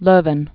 (lœvən)